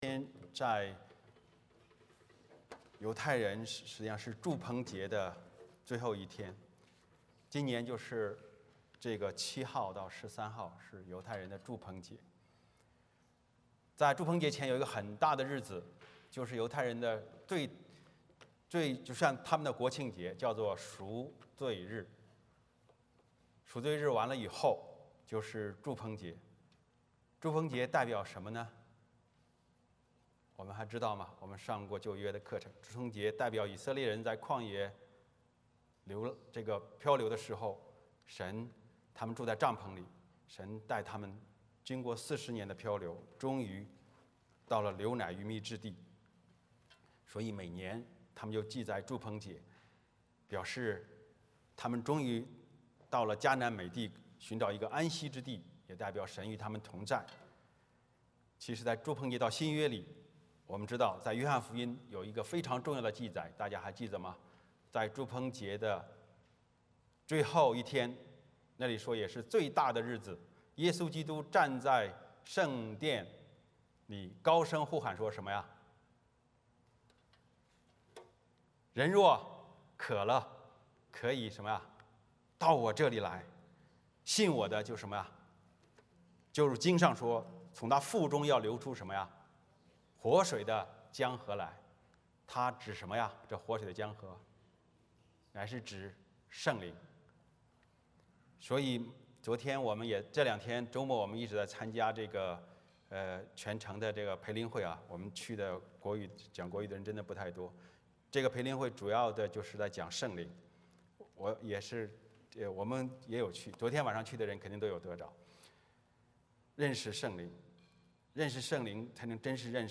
撒母耳记下3:22-39 Service Type: 主日崇拜 欢迎大家加入我们的敬拜。